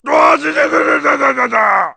Line of Klump in Donkey Kong: Barrel Blast.